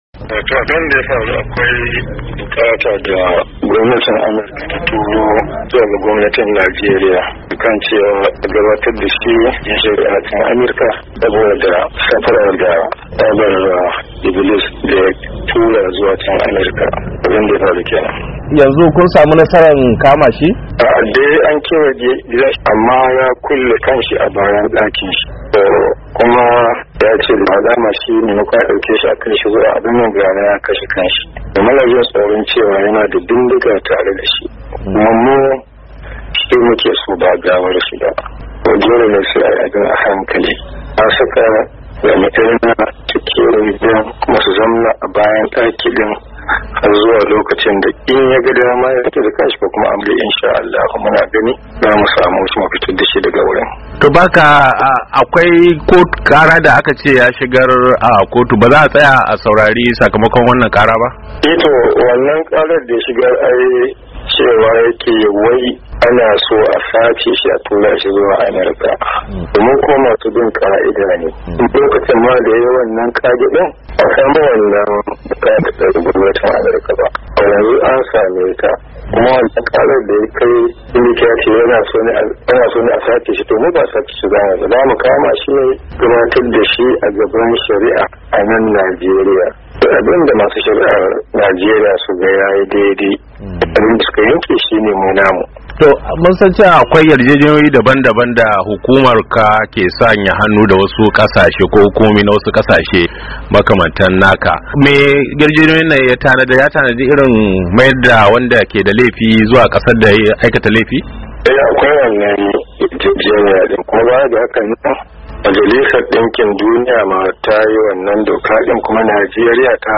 Ga cikakken rahoton.